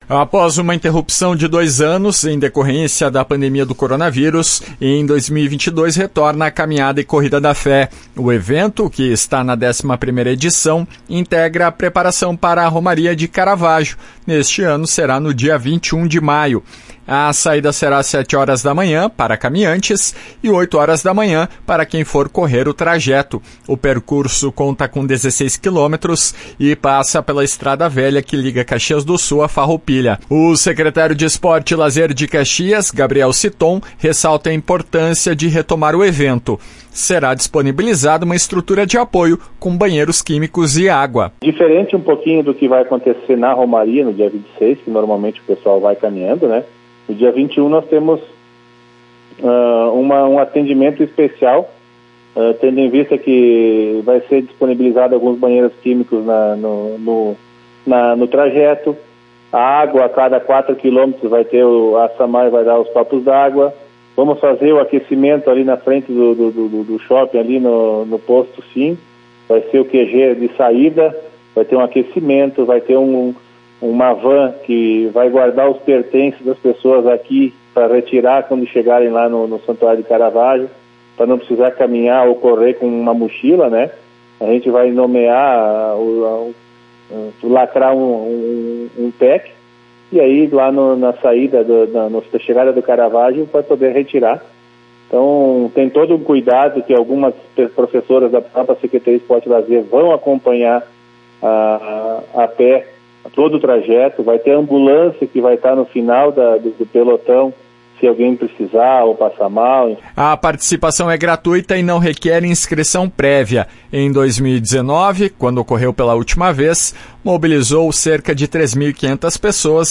O secretário de Esporte e Lazer de Caxias, Gabriel Citton, ressalta a importância de retornar o evento.